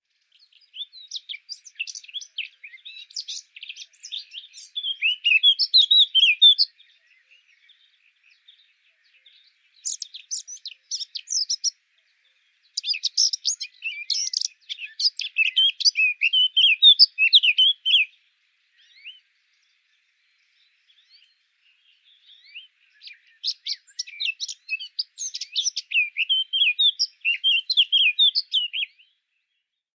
Photos de Fauvette à tête noire - Mes Zoazos